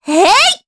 Mediana-Vox_Attack2_jp.wav